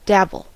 dabble: Wikimedia Commons US English Pronunciations
En-us-dabble.WAV